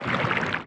Sfx_creature_spikeytrap_reel_04.ogg